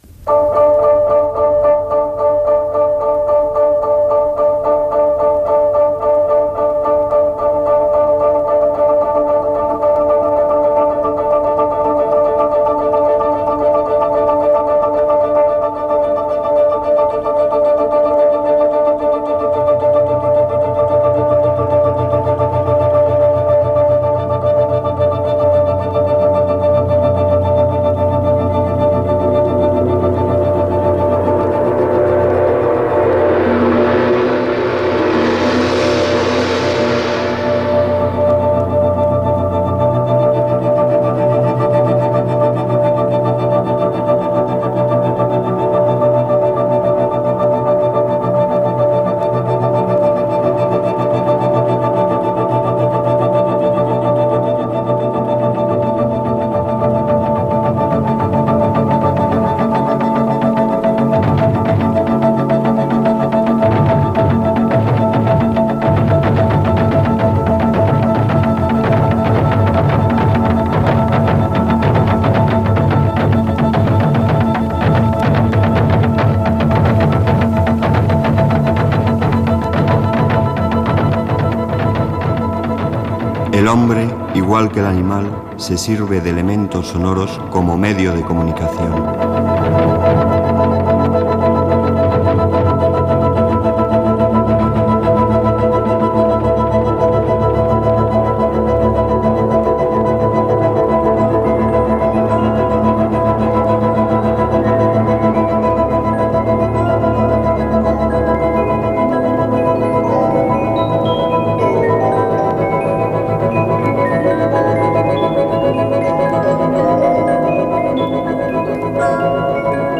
Careta del programa, presentació i espai dedicat als instruments de vent: les trompetes, caracoles i botzines
Musical